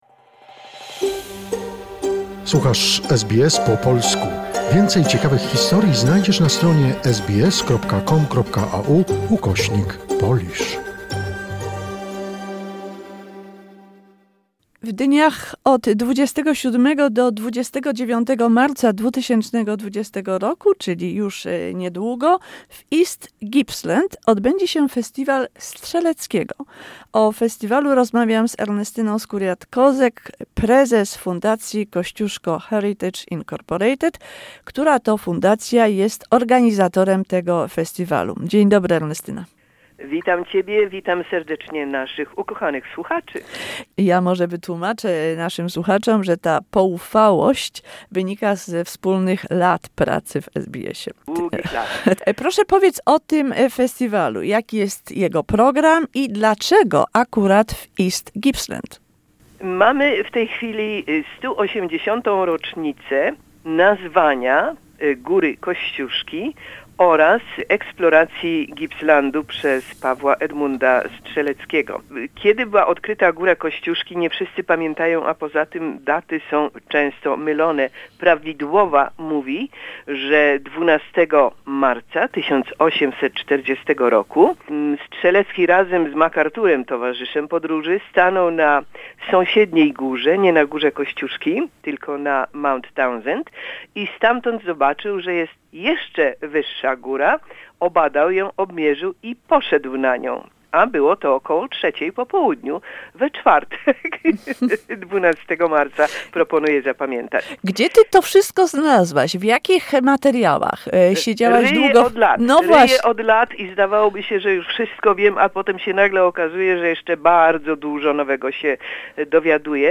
Listen to the Interview on 2GB